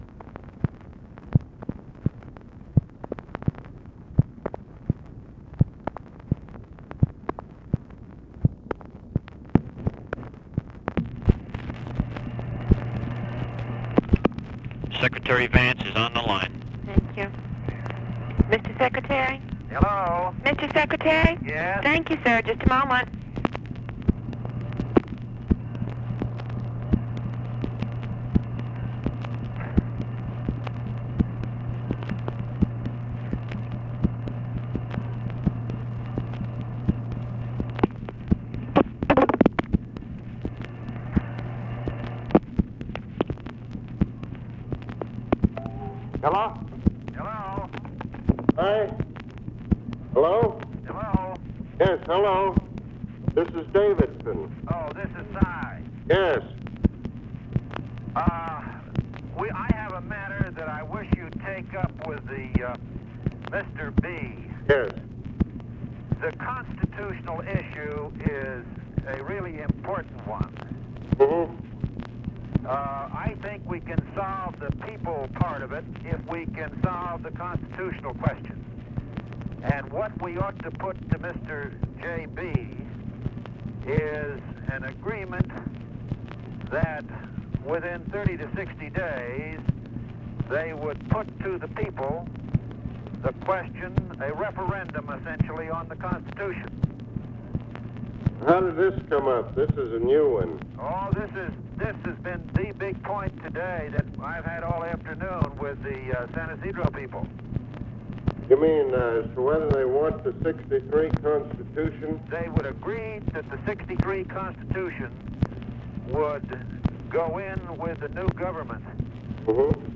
Conversation with CYRUS VANCE and ABE FORTAS, May 17, 1965
Secret White House Tapes